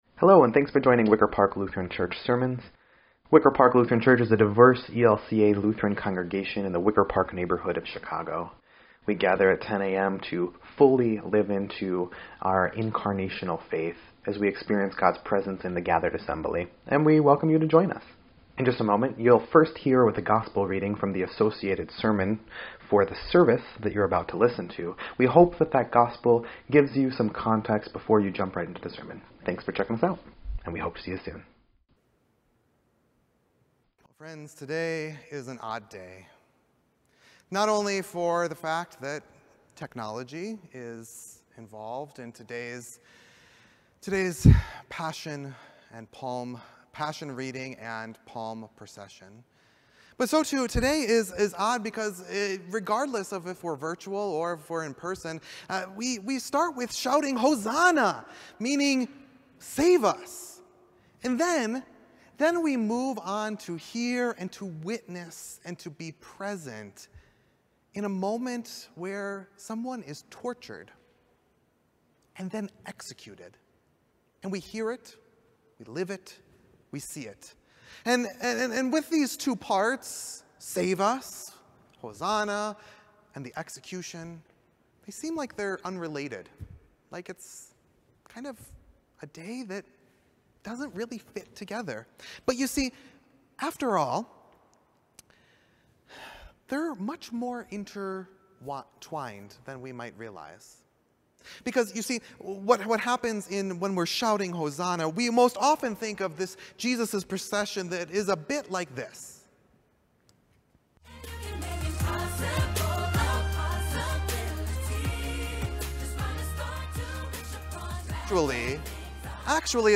introduction to the Passion Reading